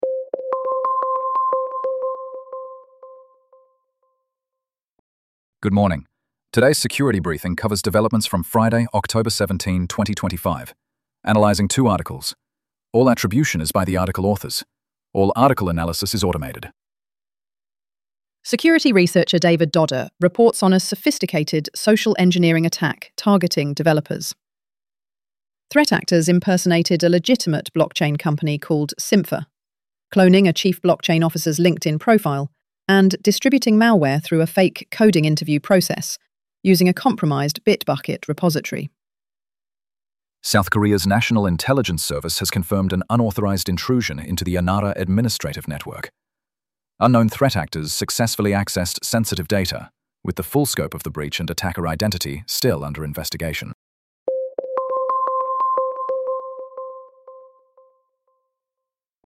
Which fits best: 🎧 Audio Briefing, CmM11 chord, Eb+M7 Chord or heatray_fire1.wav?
🎧 Audio Briefing